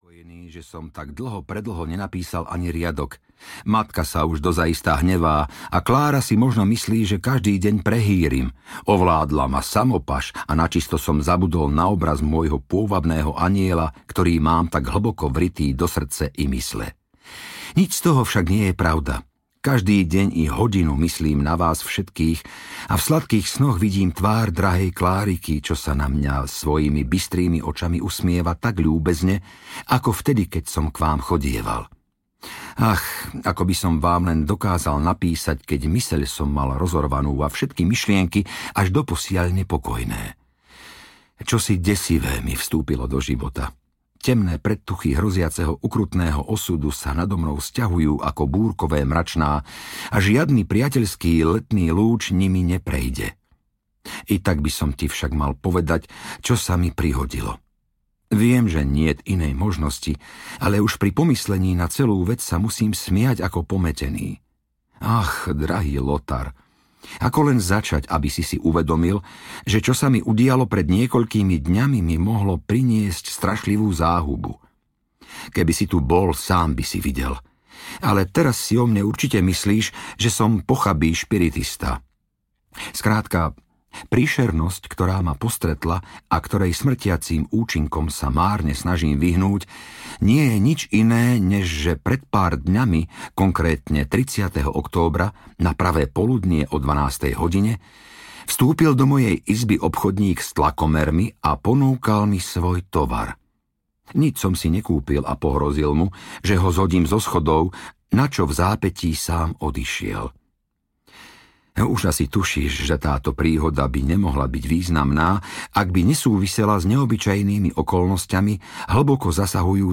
Sandmann - Piesočný démon a iné strašidelné príbehy audiokniha
Ukázka z knihy